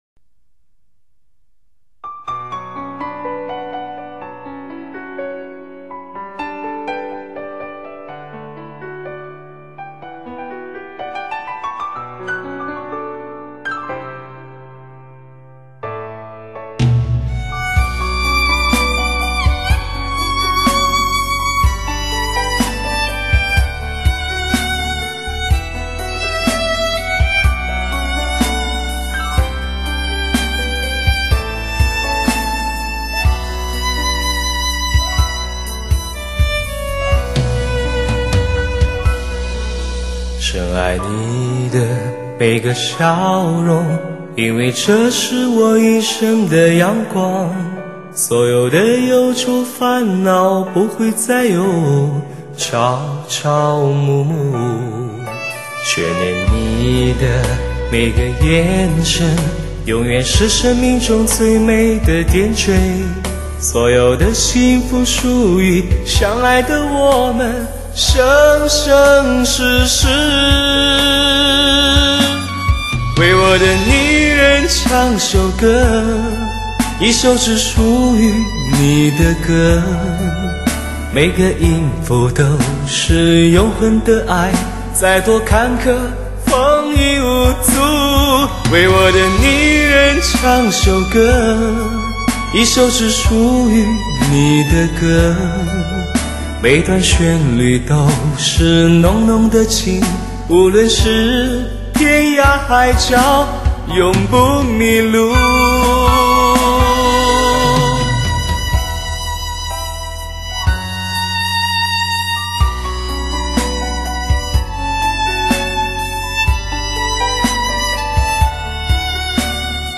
精湛录音技术，被专业人士大呼为真正的“欲罢不能”
一流，精准无误，空气感和层次感兼顾，传神到不得了！